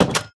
Minecraft Version Minecraft Version latest Latest Release | Latest Snapshot latest / assets / minecraft / sounds / block / iron_door / close4.ogg Compare With Compare With Latest Release | Latest Snapshot
close4.ogg